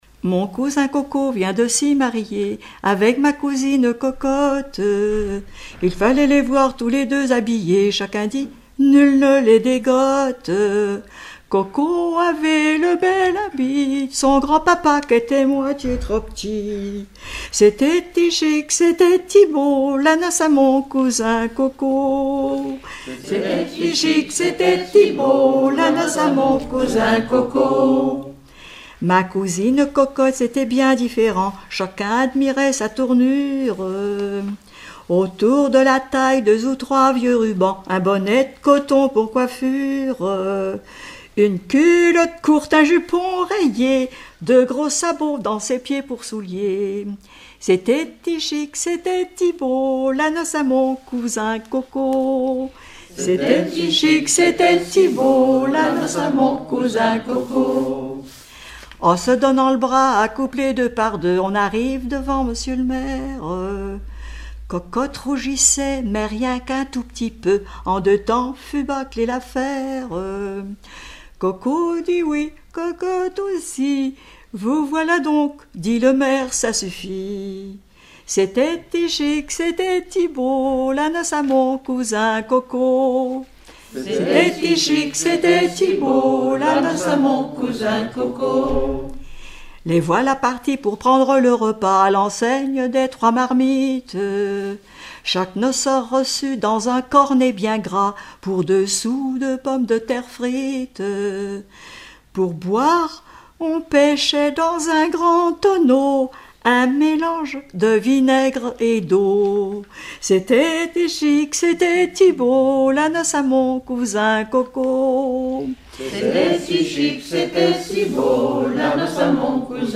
Genre strophique
Collectif-veillée (2ème prise de son)
Pièce musicale inédite